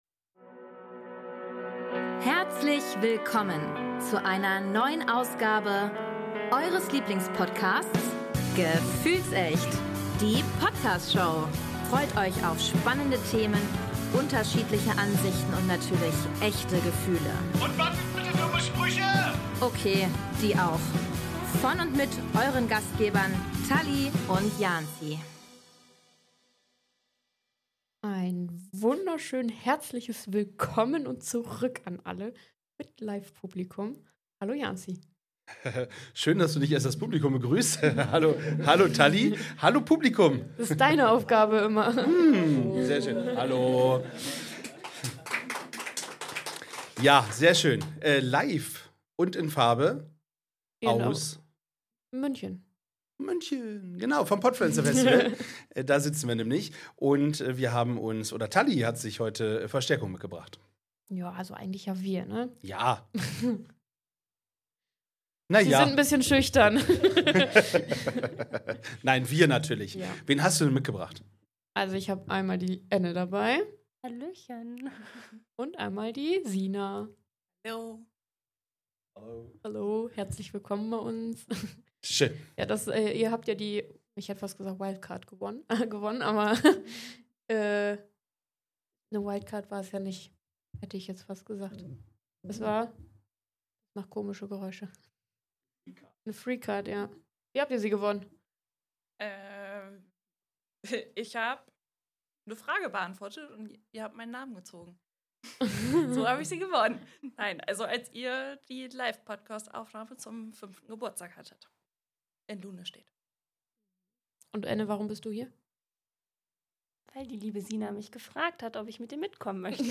31.10.2025 Inhalt: Diese Episode bringt dich direkt ins Herz des Podfluencer Festivals in München. Die Moderatoren und ihre Gäste teilen ihre persönlichen Eindrücke und sorgen für Festivalstimmung zum Mitfühlen.